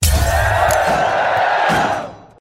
round_win.mp3